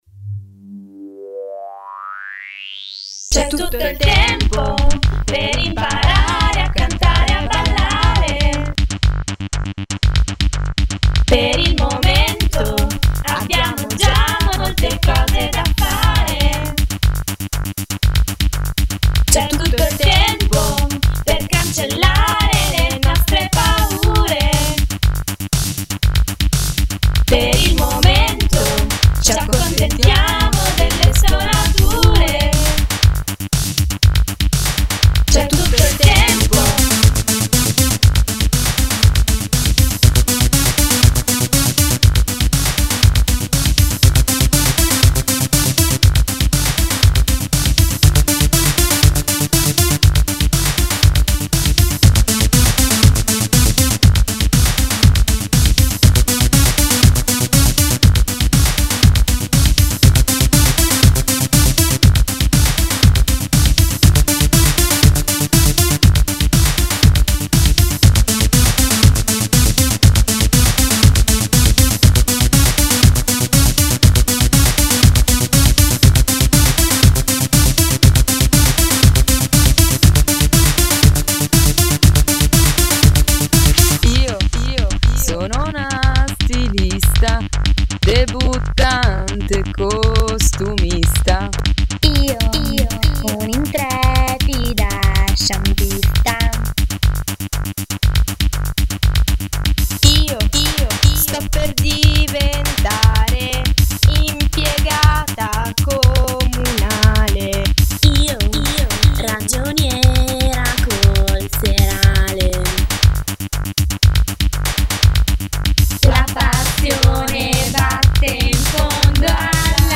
a colpi di filo spinato vocale